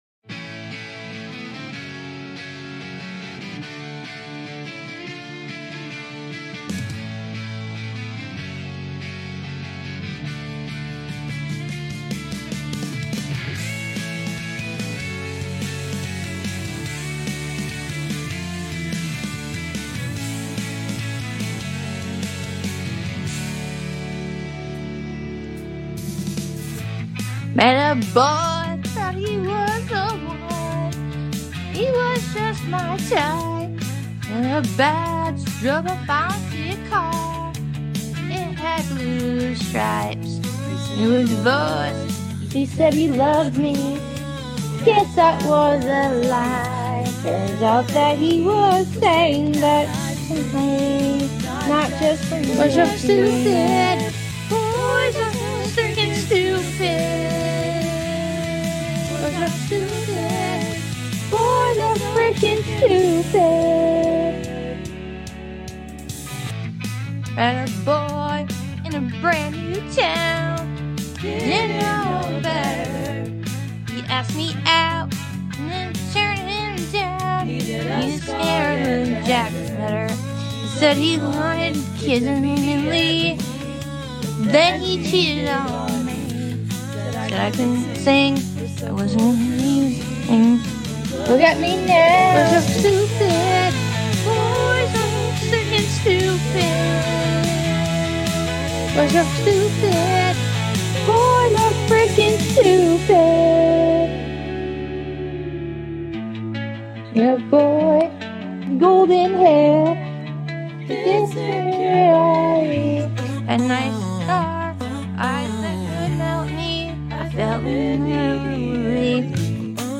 I’m singer/ Songwriter/ I can playing 🎸 My First Song Ever: Boys Sound Effects Free Download.